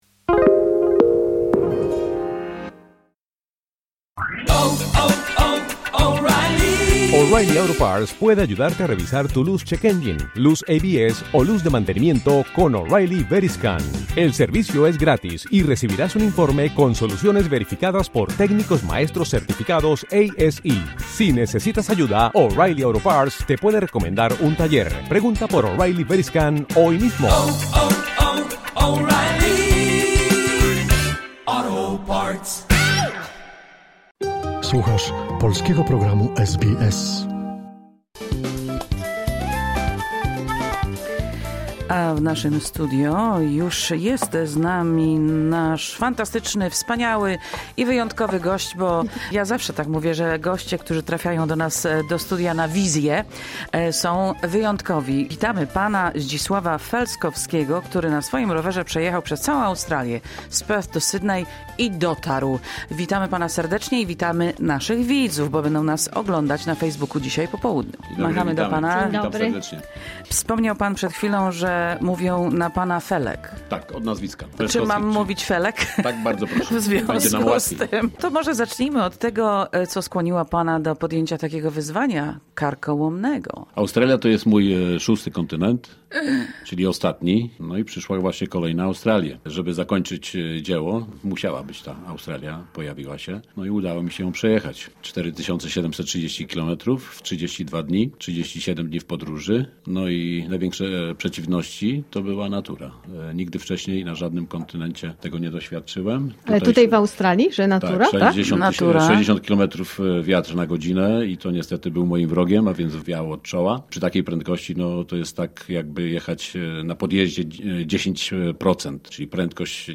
Dziś w naszym studio gościmy rowerzystę z Polski, który na swoim rowerze przejechał przez całą Australię, z Perth do Sydney.